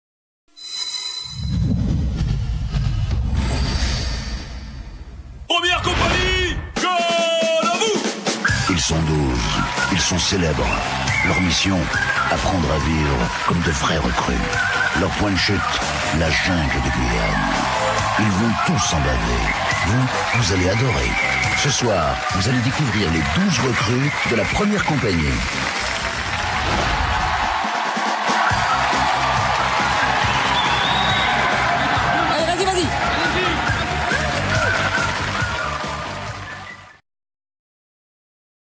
Générique